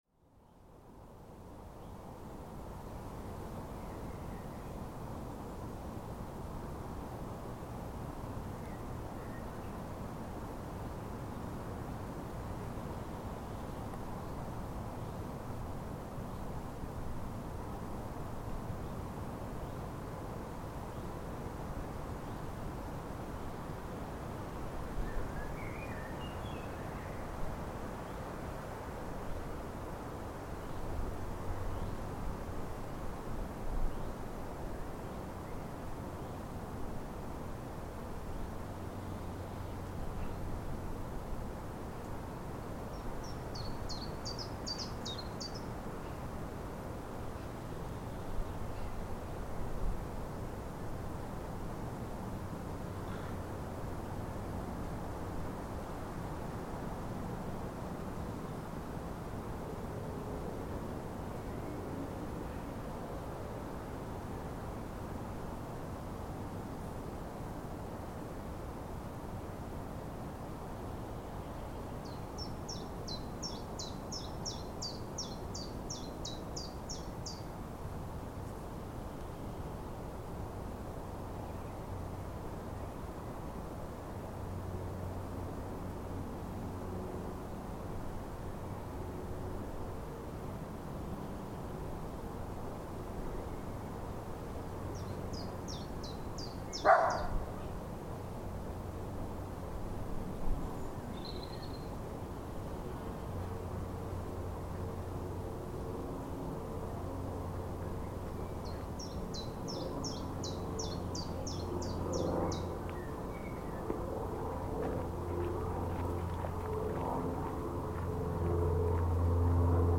Shotover is a country park within a city - a beautiful place for a forest walk within the boundaries of the city's ring road. While Oxford is lucky to have so many green spaces within its city limits, you're always reminded that you're in an urban space, as the gentle throb of traffic pervades, and here we're also in the flight path with an aeroplane passing overhead to interrupt the reverie.